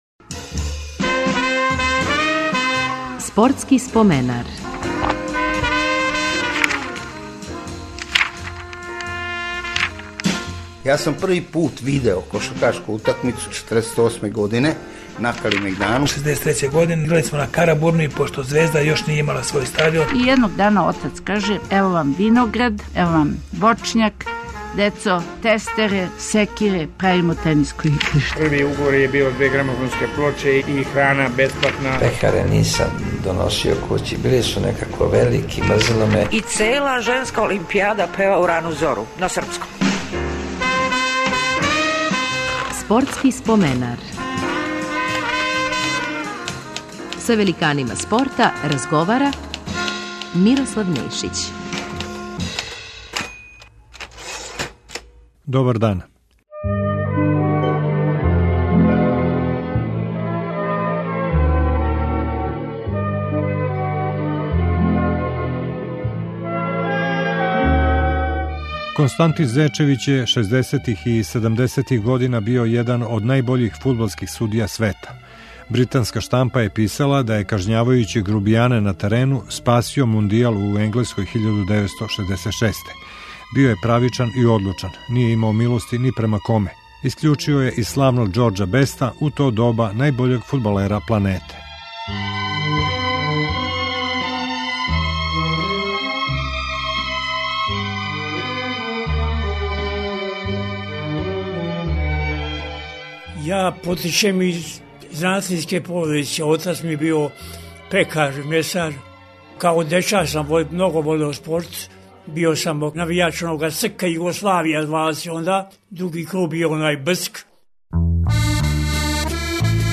Гост ће бити фудбалски судија Константин Зечевић. Током шездесетих и седамдесетих био је један од водећих светских арбитара.